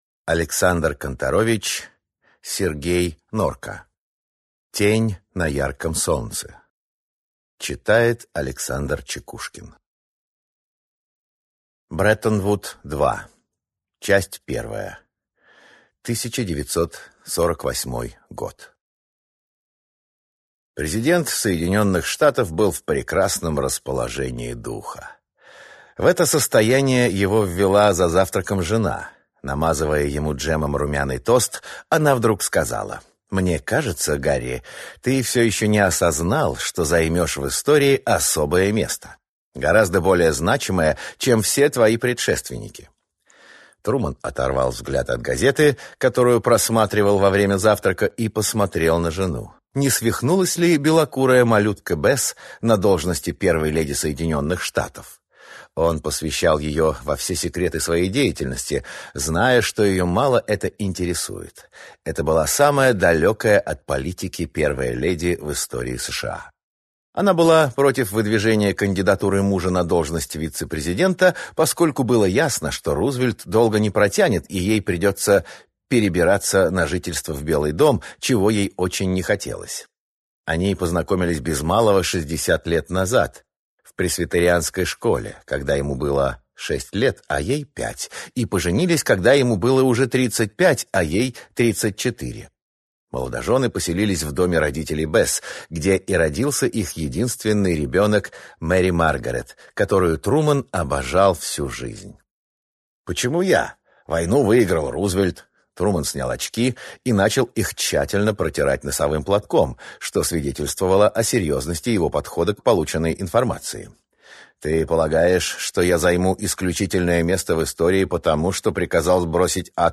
Аудиокнига Тень на ярком солнце | Библиотека аудиокниг